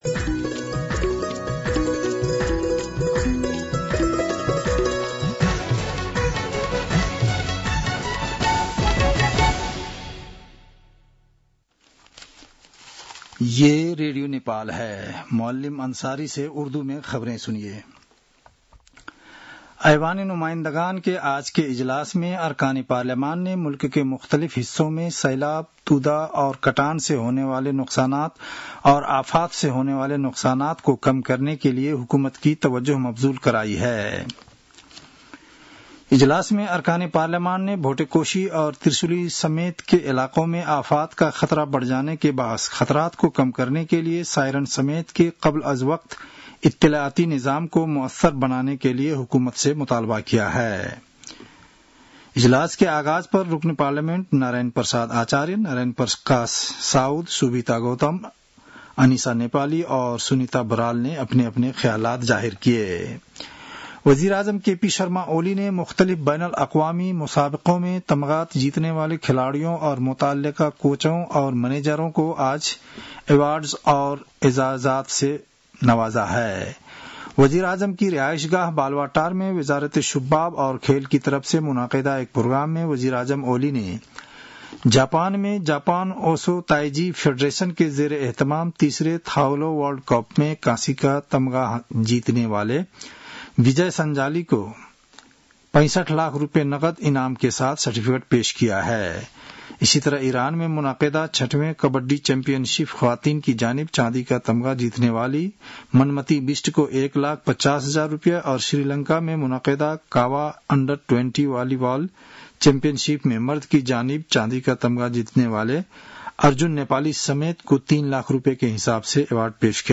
उर्दु भाषामा समाचार : २५ असार , २०८२